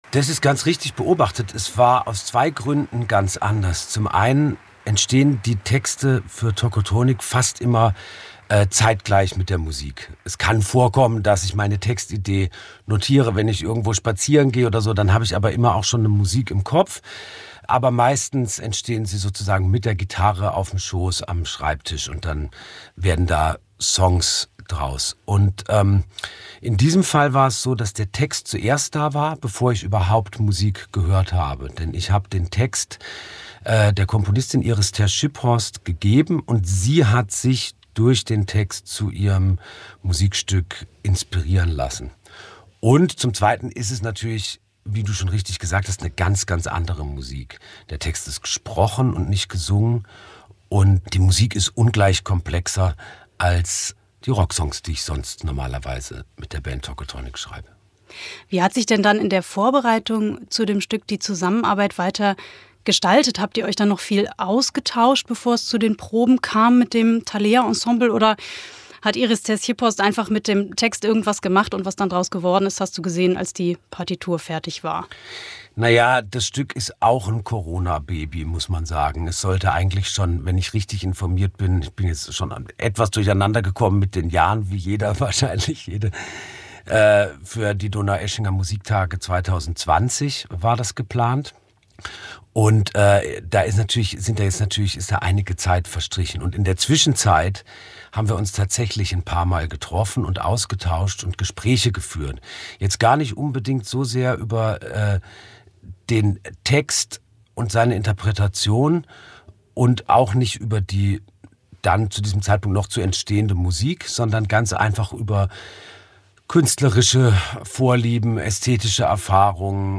Dirk von Lowtzow, Frontmann der Band Tocotronic im Gespräch
Interview